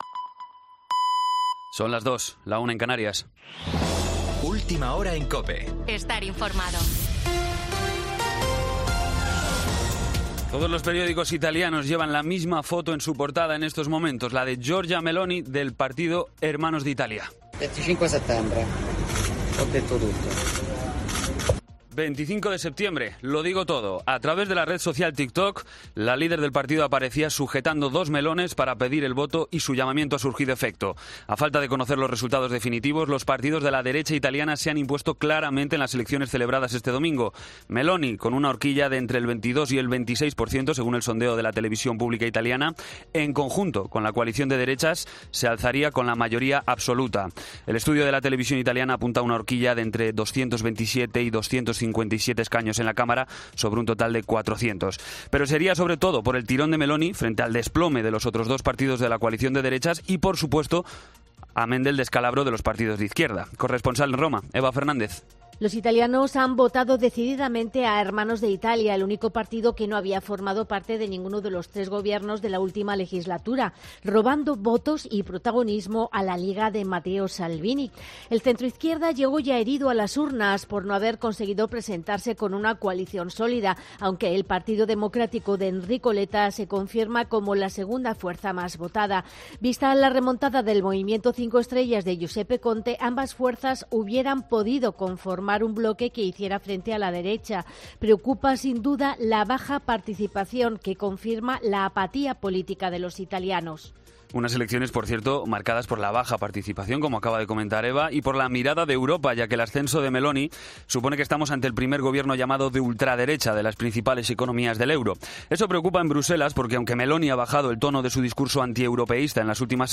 AUDIO: Actualización de noticias Herrera en COPE